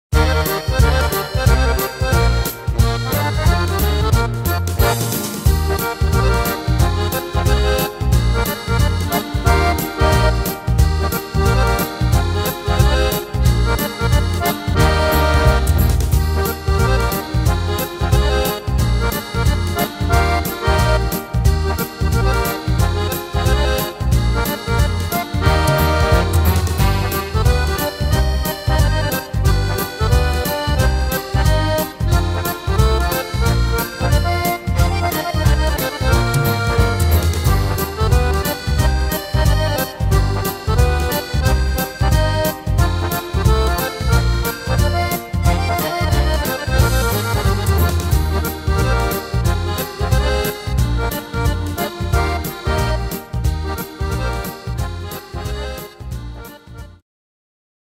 Tempo: 180 / Tonart: Eb-Dur